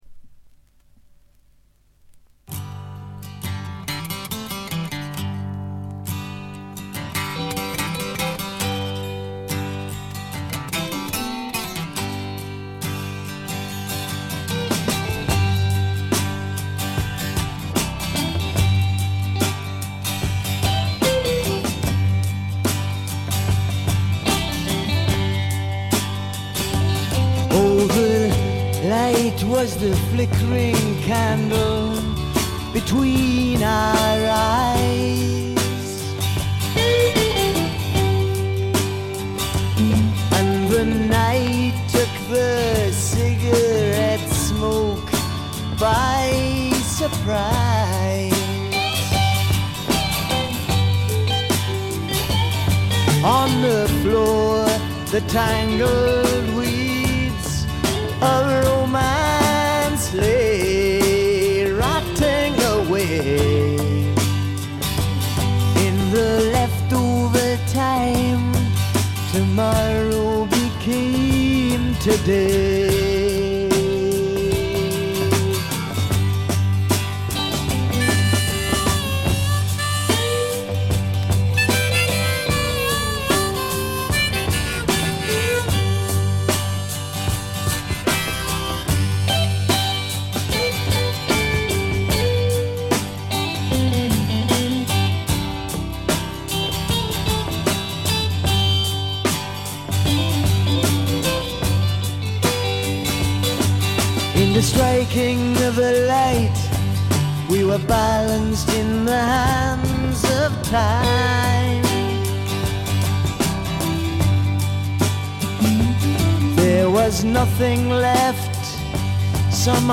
英国産「木漏れ日フォーク」には程遠い、荒涼とはしているものの気品のある風景画のような楽曲群です。
試聴曲は現品からの取り込み音源です。